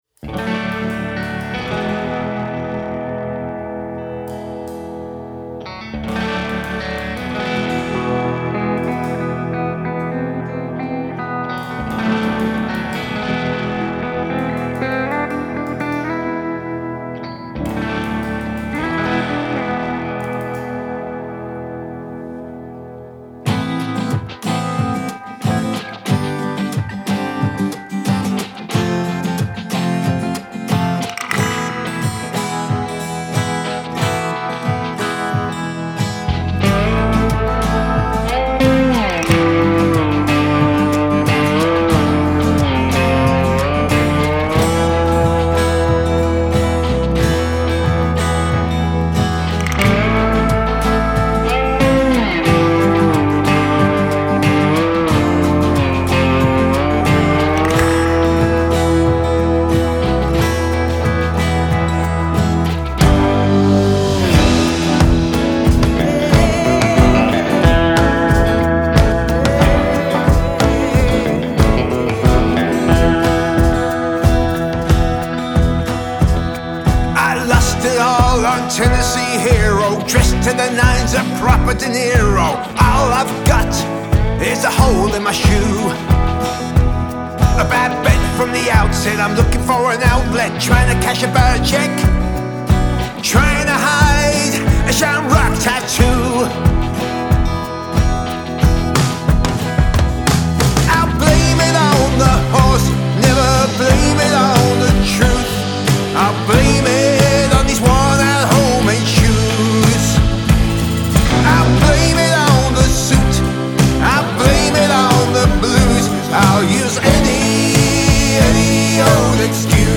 Male Vocal, Guitar, Lap Steel, Bass Guitar, Drums